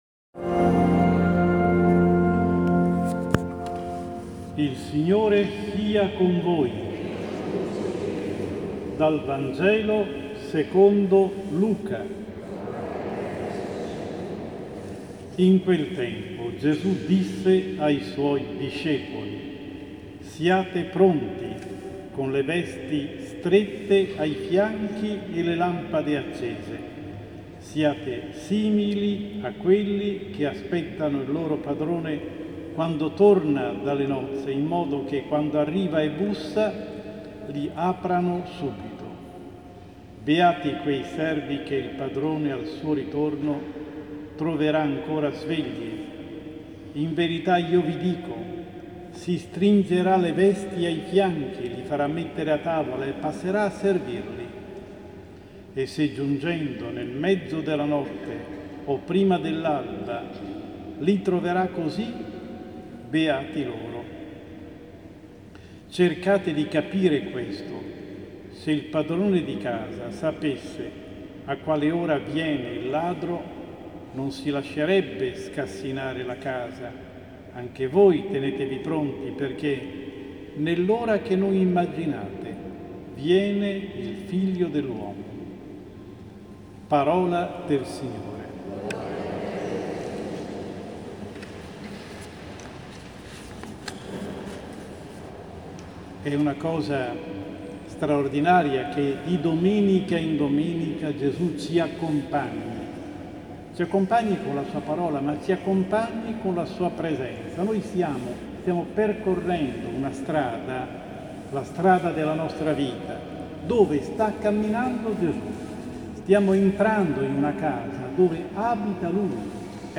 Omelia domenica XIX. 7 agosto 2016